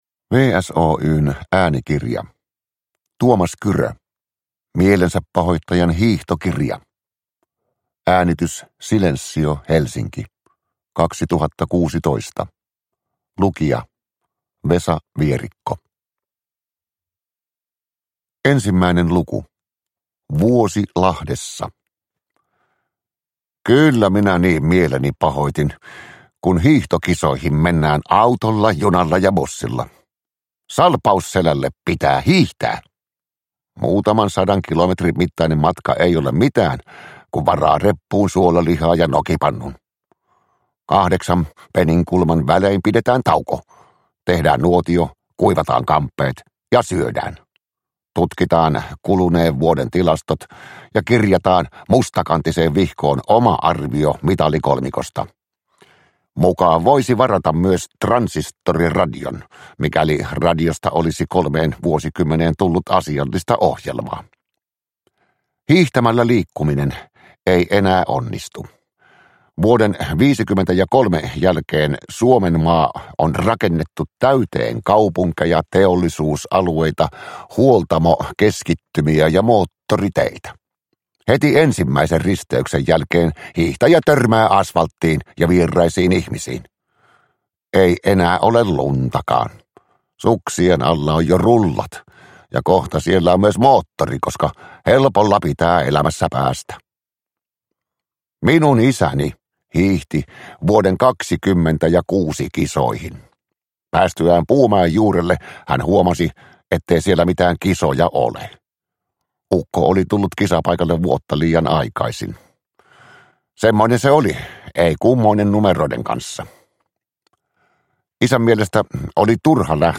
Mielensäpahoittajan hiihtokirja – Ljudbok
Uppläsare: Vesa Vierikko